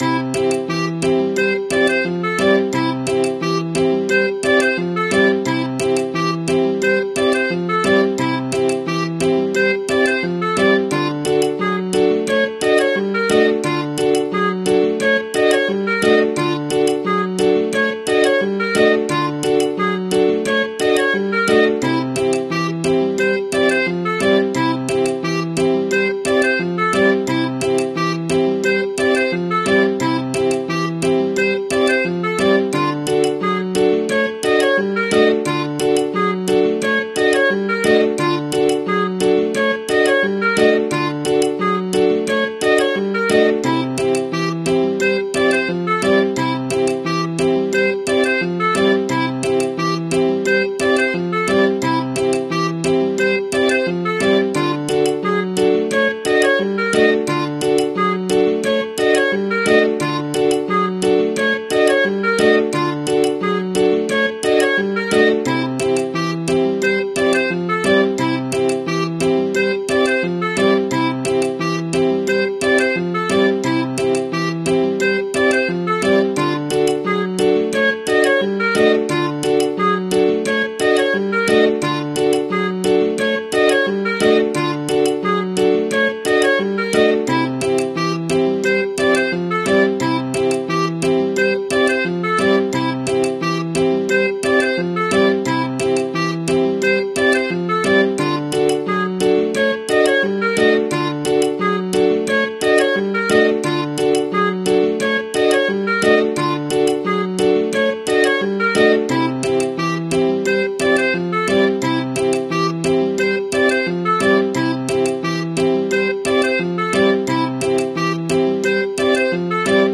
Eating noodles 😂🤤🤤🤤🤤 gone sound effects free download
You Just Search Sound Effects And Download. tiktok comedy sound effects mp3 download Download Sound Effect Home